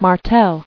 [Mar·tel]